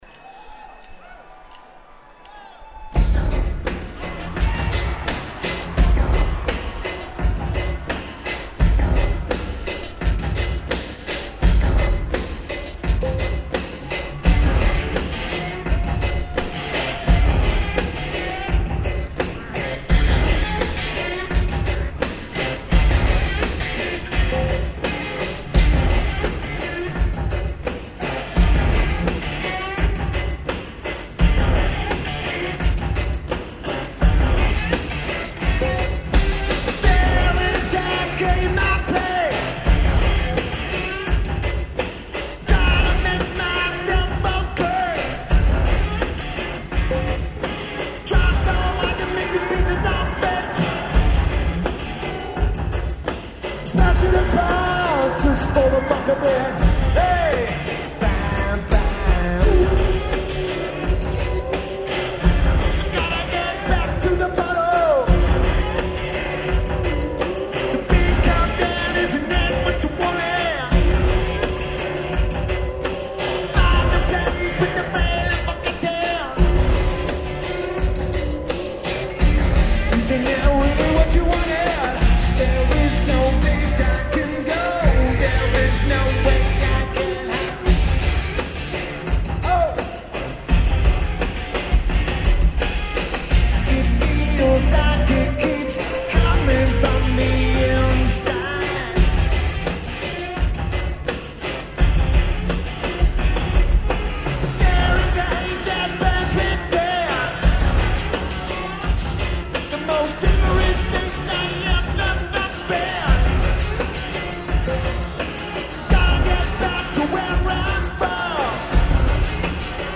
Blossom Music Center
Lineage: Audio - AUD (AT853 (4.7k mod) + Sony PCM-A10)